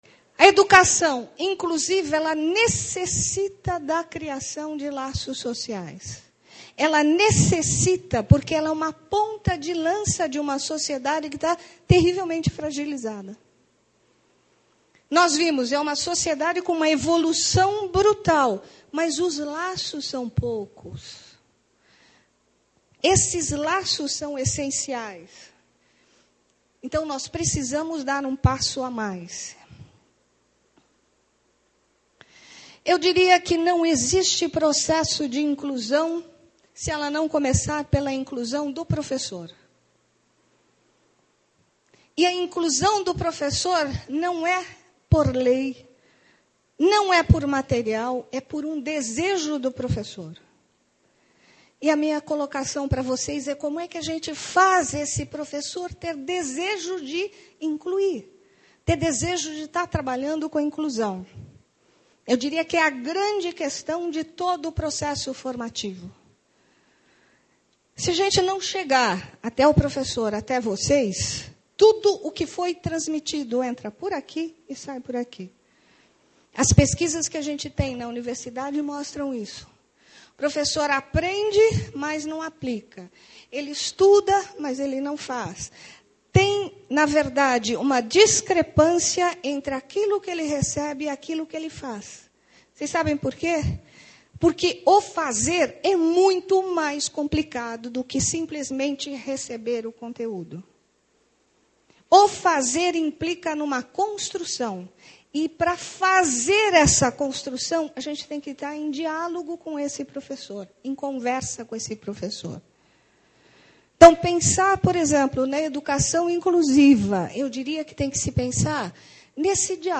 Durante o evento, que contou mais de 400 participantes, falou-se sobre a legislação em vigor, as experiências aplicadas, pesquisas realizadas, sobre os compromissos e responsabilidades da escola e do professor, sobre formação docente.